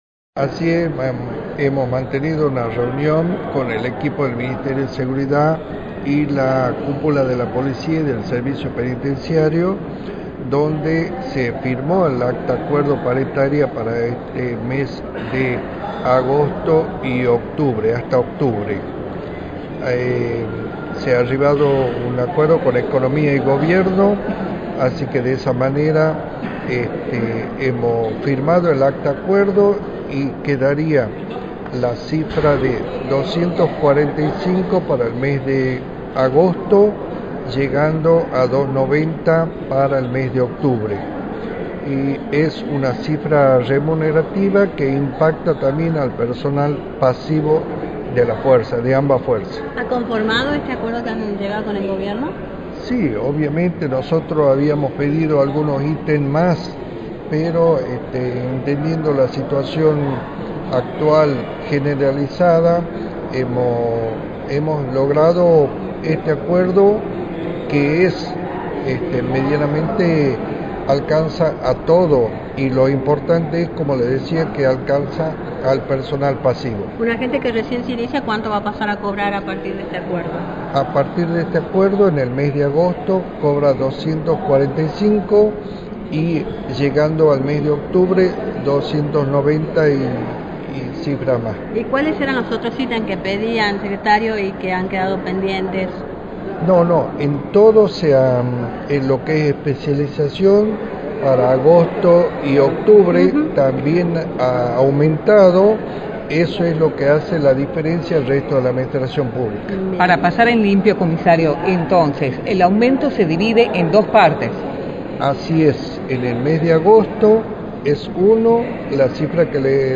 Luís Ibáñez, Secretario de Seguridad, informó en Radio del Plata Tucumán, por la 93.9, los avances en las negociaciones paritarias de la policía
Secretario-de-Seguridad.-Luis-Ibanez-DEL-PLATA.mp3